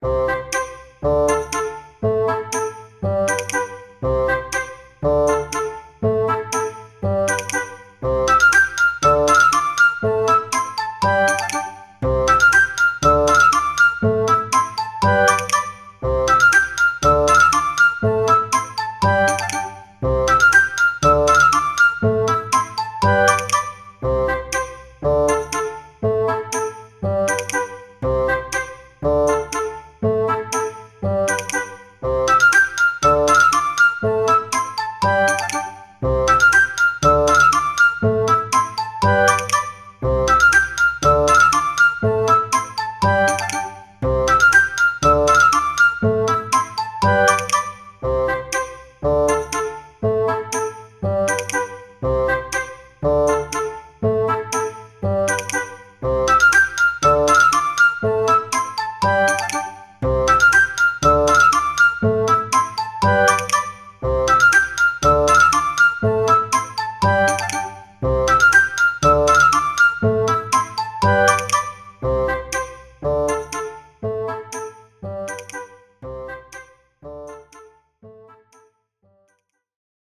BPM 120
クラリネット
オーボエ
フルート